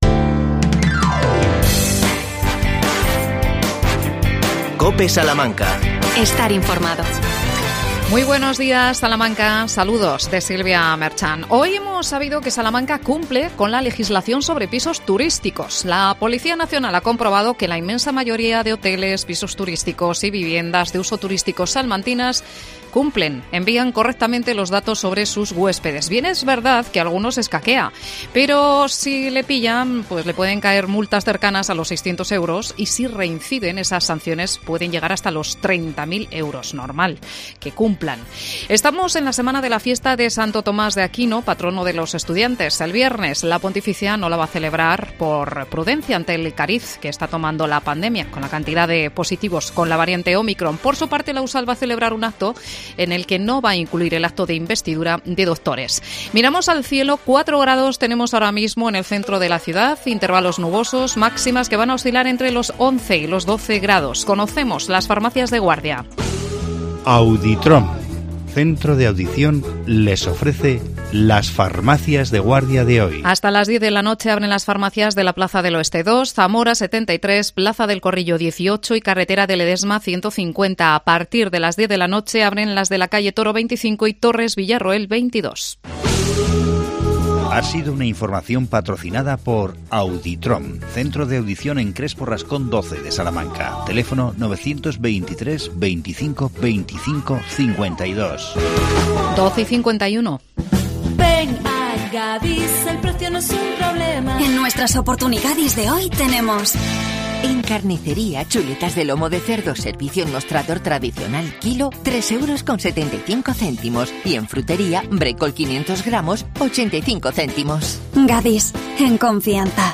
AUDIO: Entrevista al concejal de Fomento Fernando Carabias sobre la nueva promoción de viviendas en Ciudad Jardín.